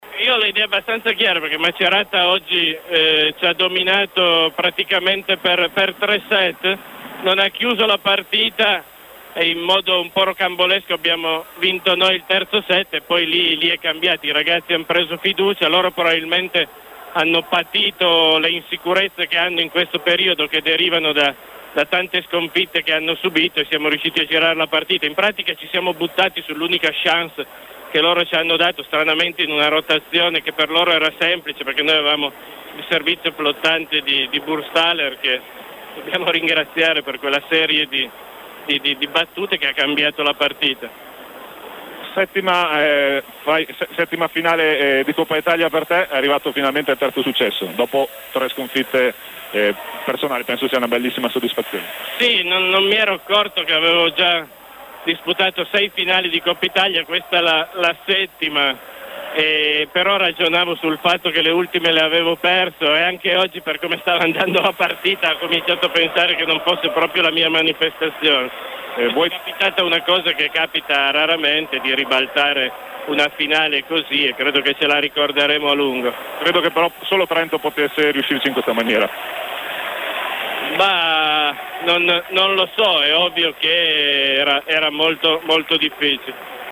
Interviste mp3